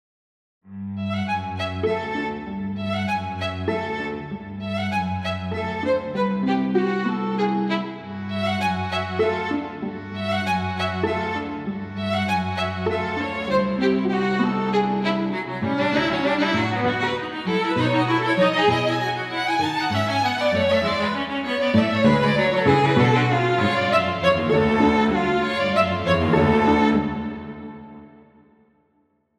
Divertimento and Variations for String Quartet
So this is a variations piece (once again) that I wrote based on my own original theme meant to explore cross-relations in a poly-tonal context.